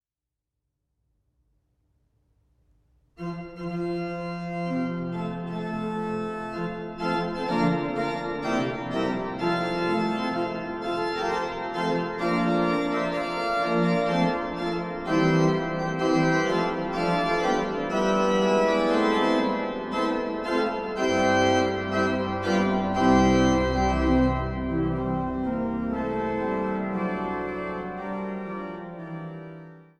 Jehmlich-Orgel in der Kirche St. Wolfgang zu Schneeberg